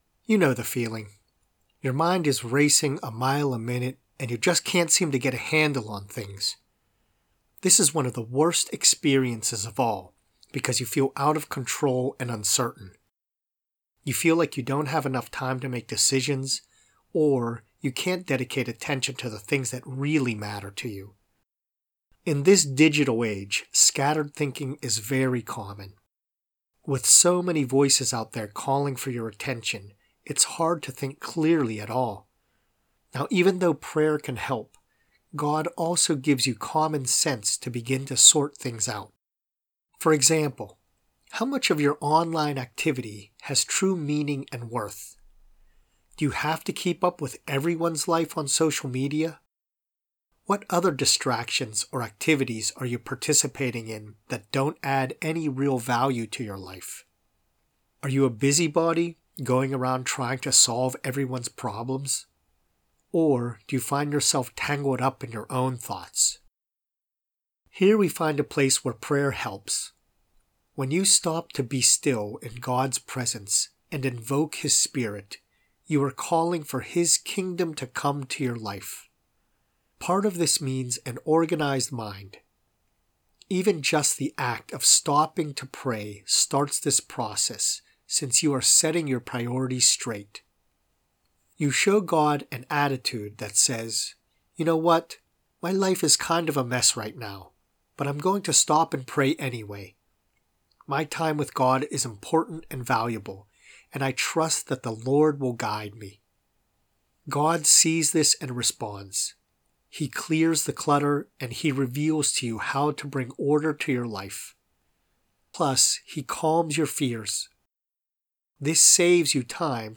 prayer-for-a-clear-mind.mp3